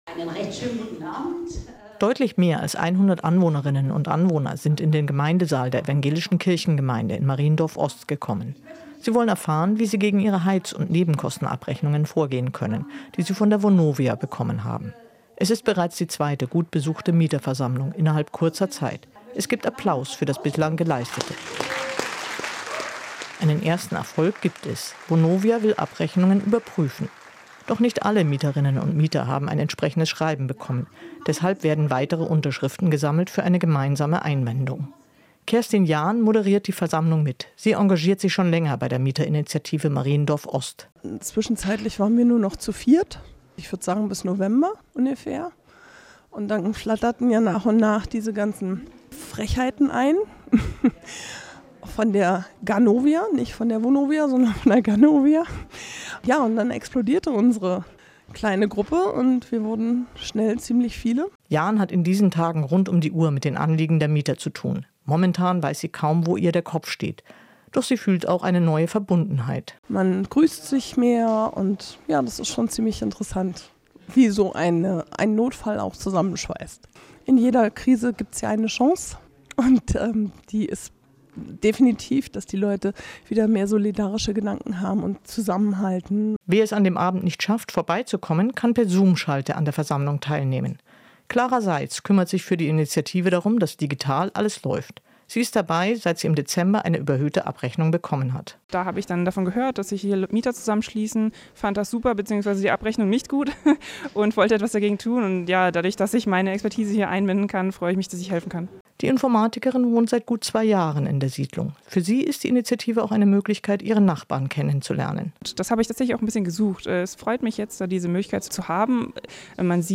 Mieterversammlung in Mariendorf
Manchmal entsteht Zusammenhalt an überraschenden Orten: Etwa in einer Anwohnerinitiative, wenn unliebsame Schreiben des Vermieters ins Haus flattern, wie wir bei einer Mieterversammlung beobachtet haben.